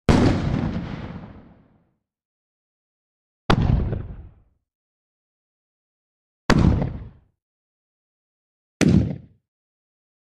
Звуки гранаты
Взрыв гранаты вариант 2